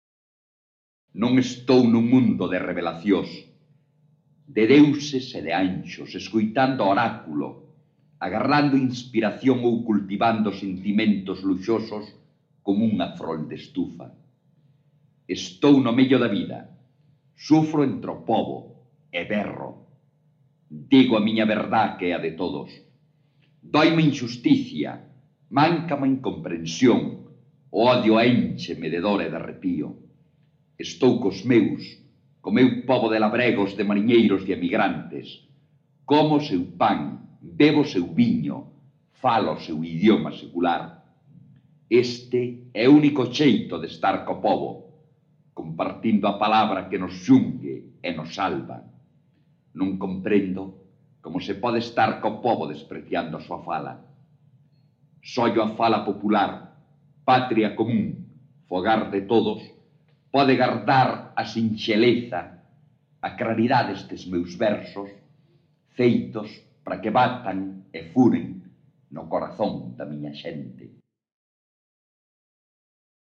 Autoría: Manuel María   Intérprete/s: Manuel María